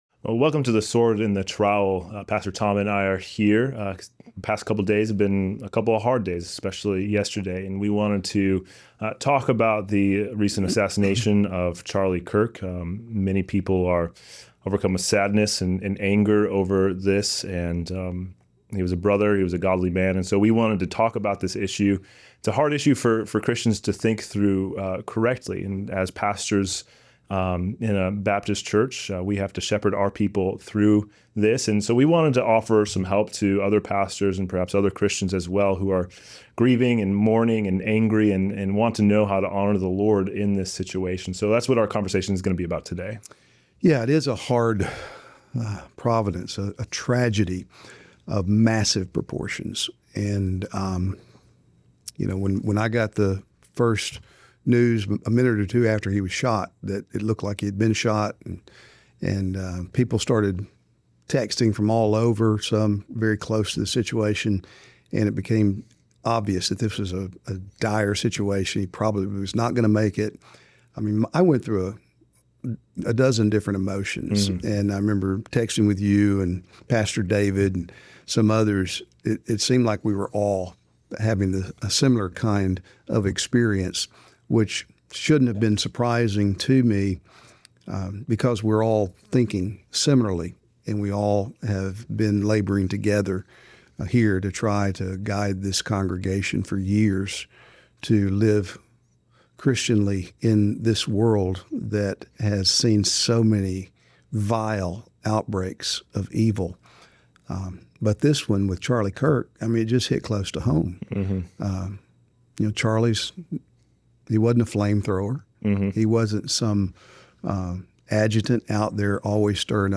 Today on this unique LIVE episode of The Sword and The Trowel podcast